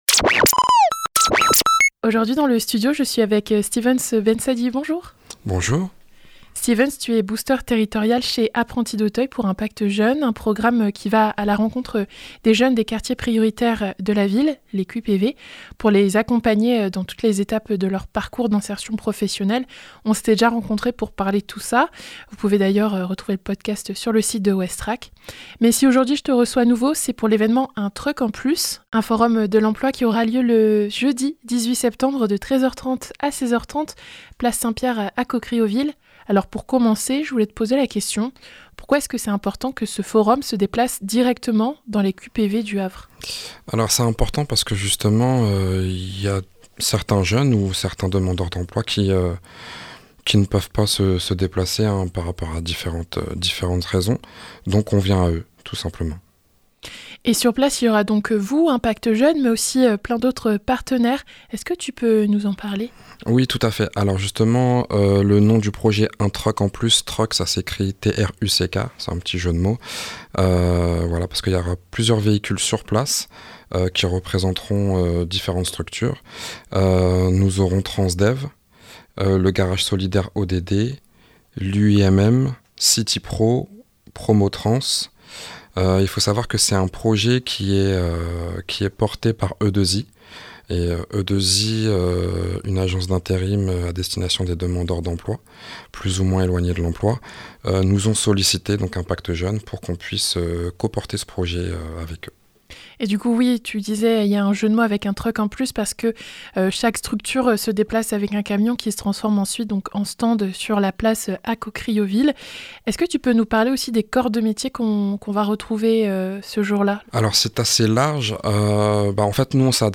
Il sera présent le jour de l'évènement et il nous en parle d'avantage dans cette interview.